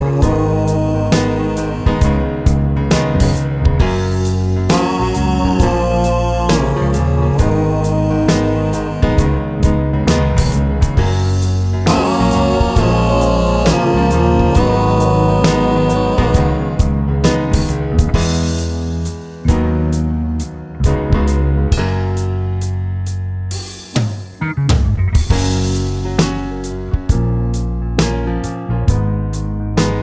No Guitar Solo Soul / Motown 4:28 Buy £1.50